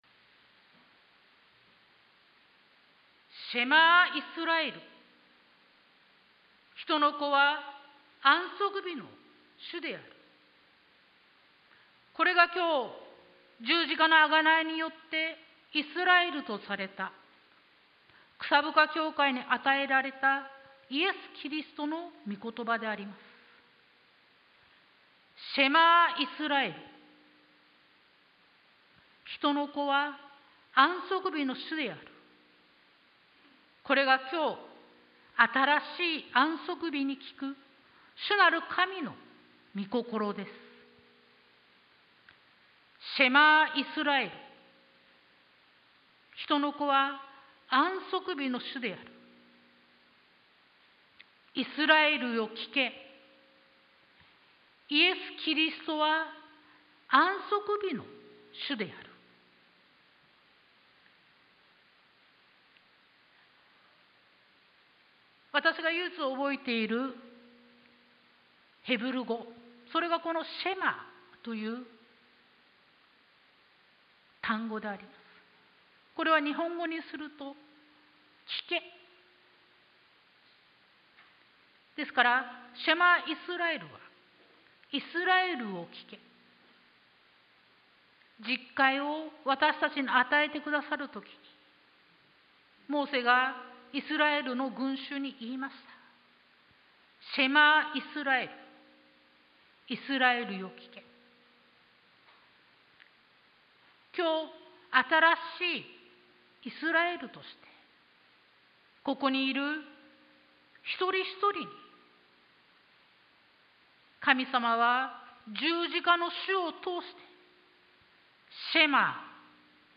sermon-2022-08-07